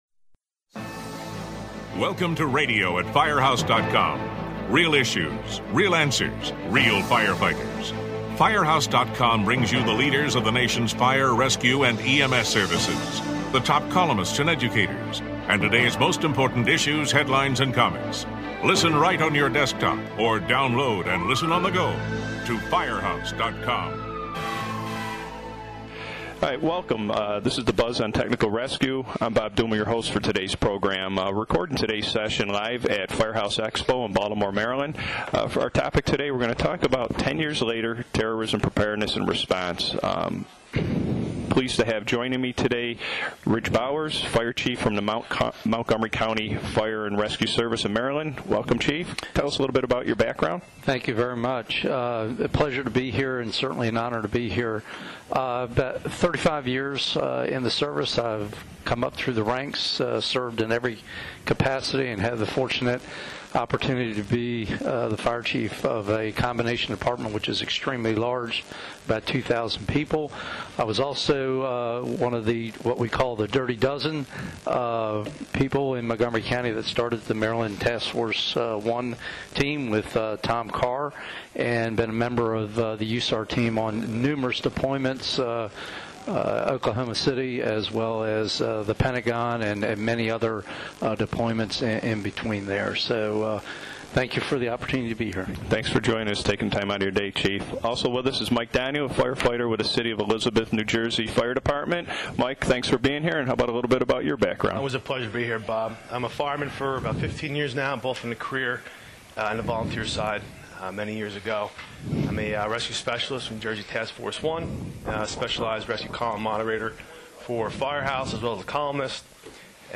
The four veteran technical rescue responders discuss their department's involvement and preparedness before the terrorist attacks.